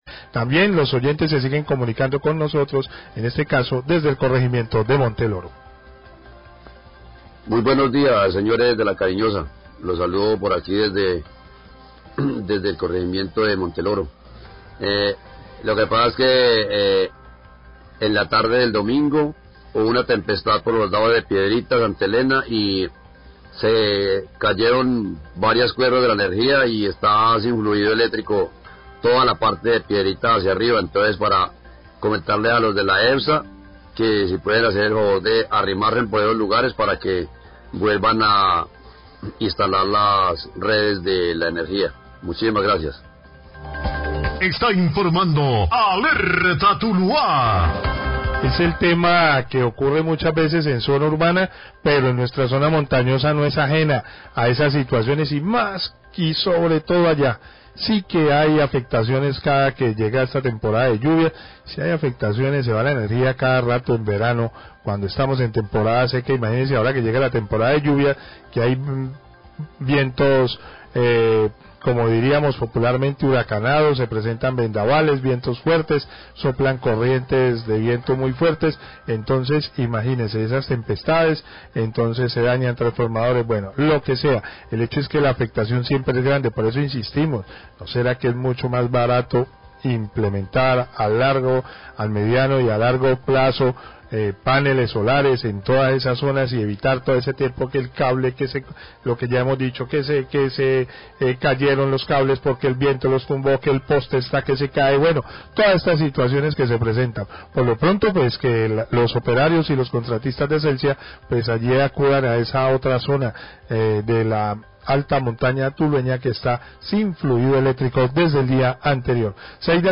Radio
Oyente desde el corregimeinto Monteloro de Tuluá, envia un audio por whatsapp, informando que desde la tarde del domingo pasado se presentó un corte de enería debido a la caída de redes de energía por una tormenta que azotó la zona. Pide a Celsia enviar personal para restabelcer el servicio.